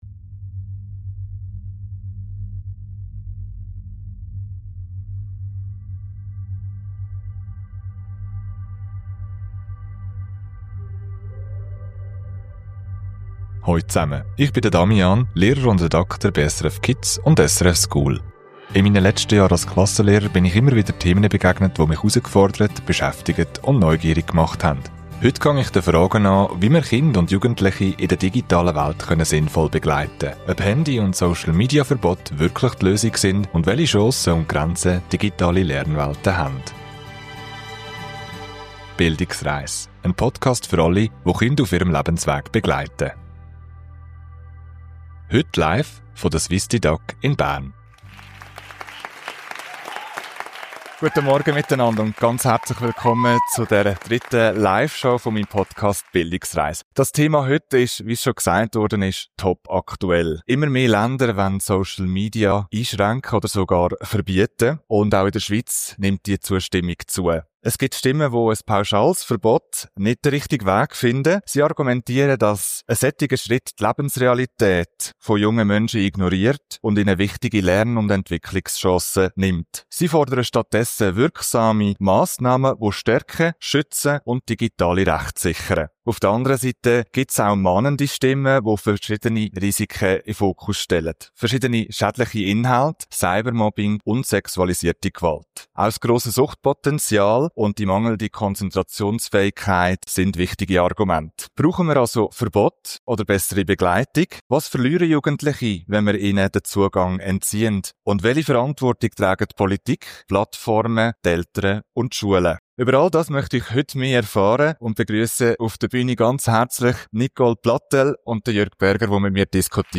Live: Digitale Bildung – Wie viel Digitalität verträgt die Schule?